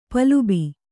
♪ palubi